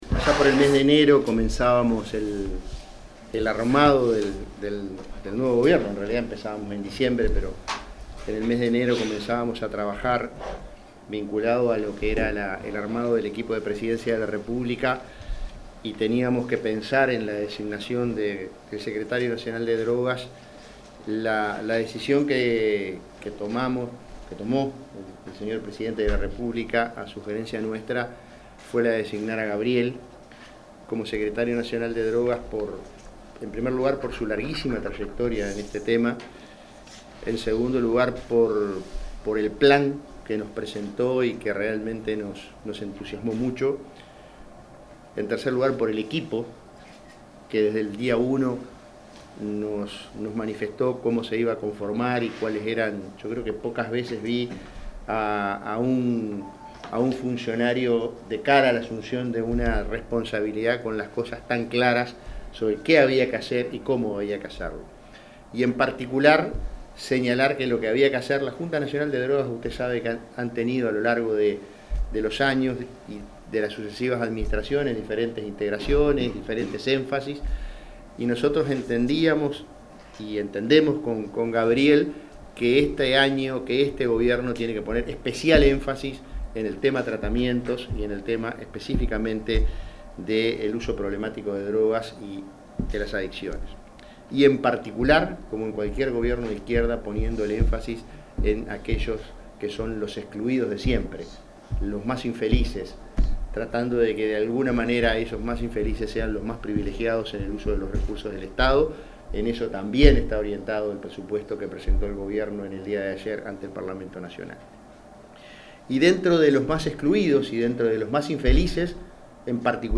Palabras del prosecretario de Presidencia, Jorge Díaz
El prosecretario de la Presidencia y presidente de la Junta Nacional de Drogas, Jorge Díaz, se expresó durante la firma de un convenio con la